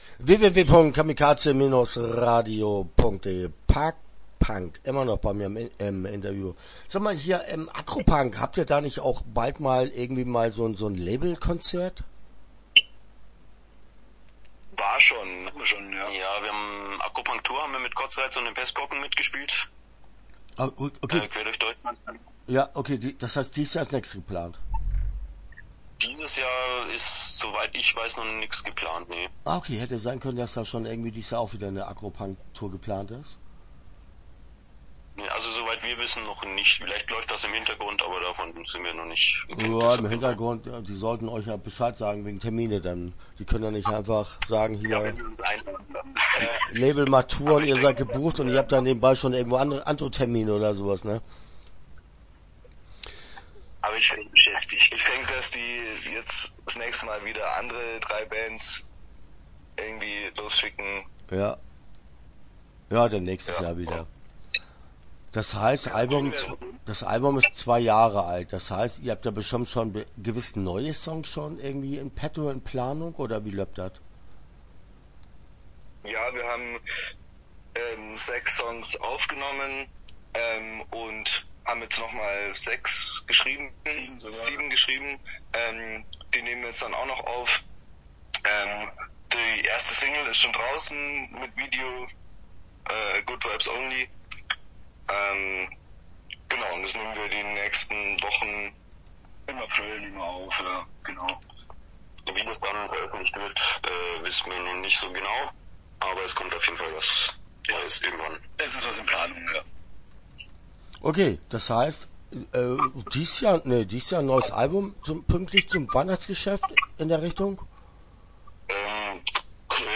Start » Interviews » ParkPunk